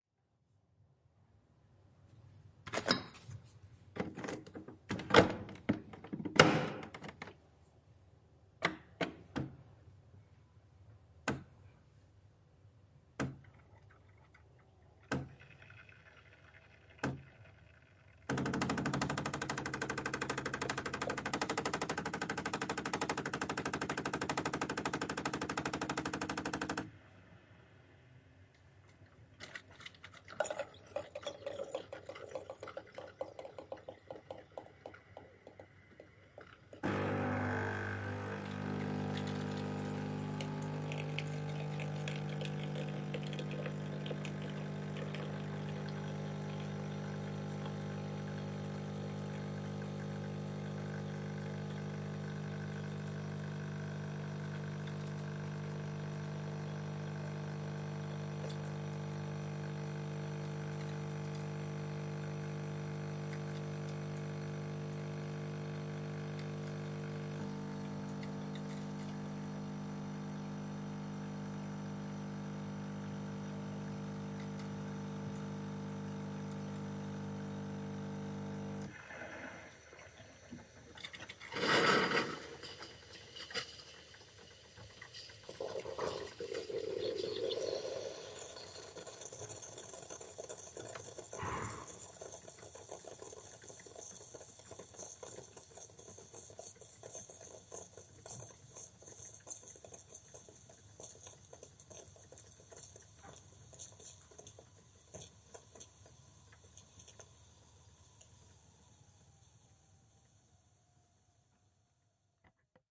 咖啡机
描述：咖啡机从豆荚里煮一杯咖啡。
Tag: 咖啡机 酿造 咖啡